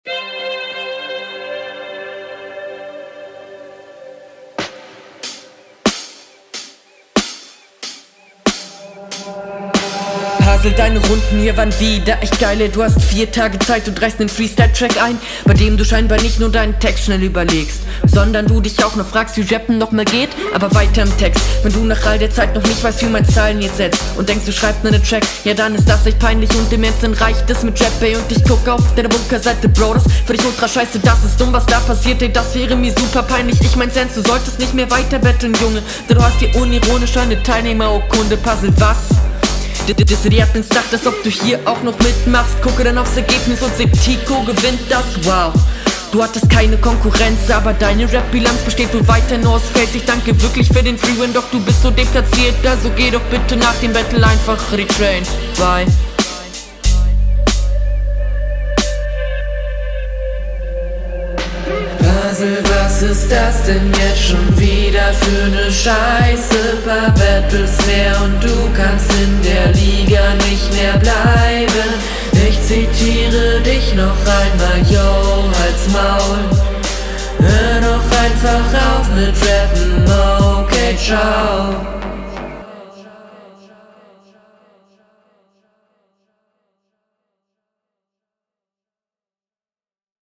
Flow: Vom Stimmeinsatz her finde ich das sehr störend.